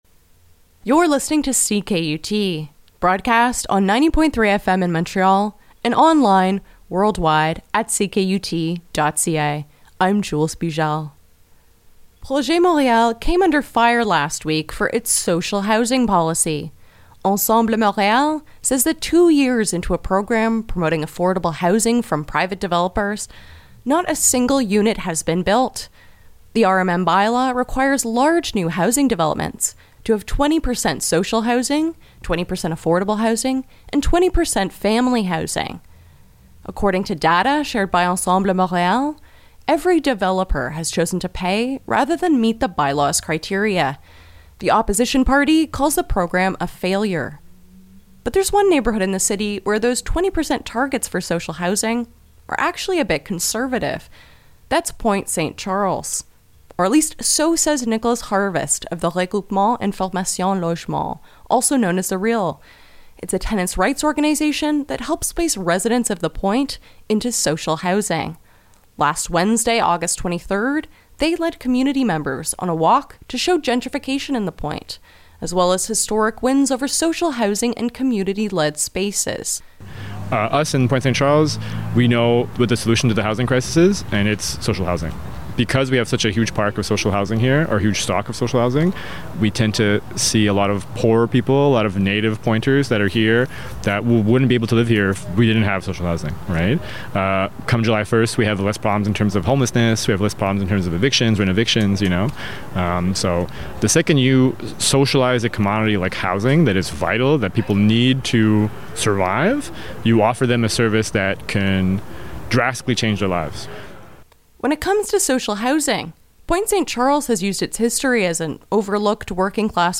CKUT attended a community walk led by the Regroupement Information Logement (RIL) to learn about Pointe-Saint-Charles’s history of organizing around housing and the gentrification happening in the borough.
PSC-anti-gentrification-walk.mp3